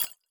Futuristic Sounds (22).wav